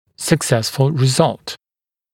[sək’sesfl rɪ’zʌlt] [-ful][сэк’сэсфл ри’залт] [-фул]успешный результат